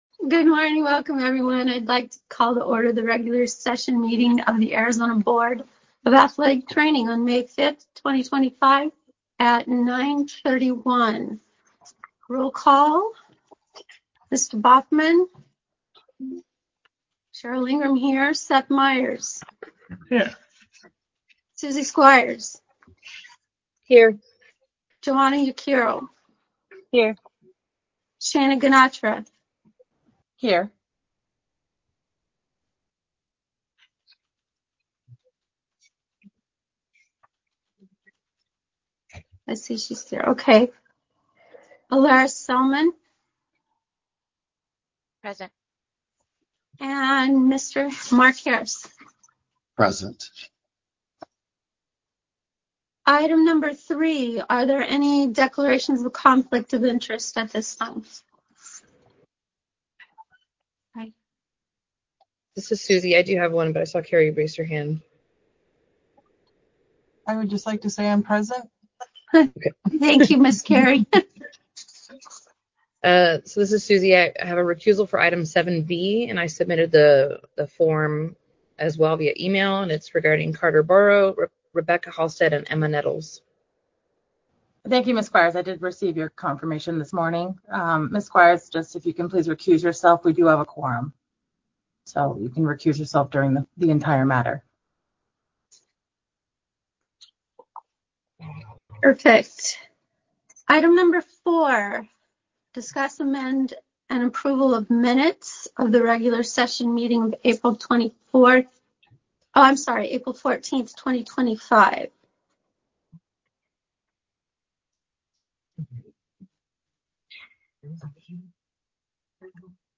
May Athletic Training Board Meeting.mp3